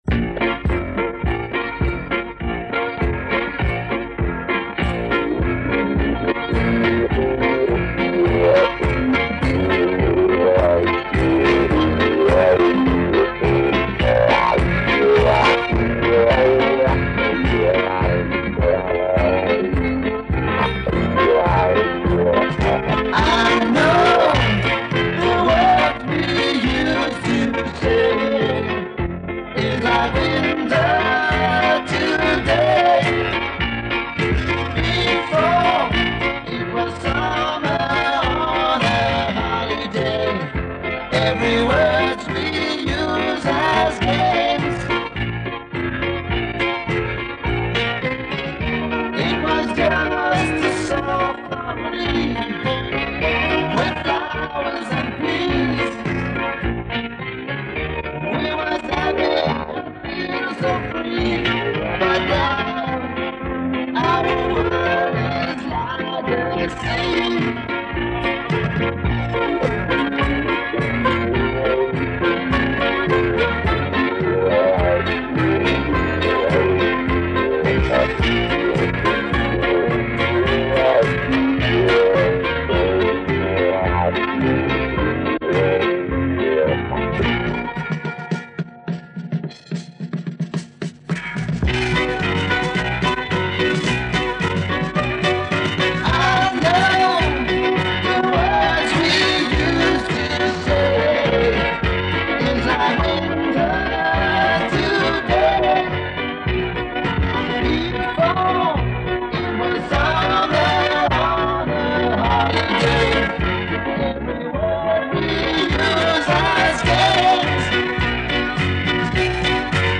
Запись сделана в 70 годы, Вторая половина то ли радио , то ли телевизор. 2 куплета. Показалось короткой, сделал перезаписью повтор.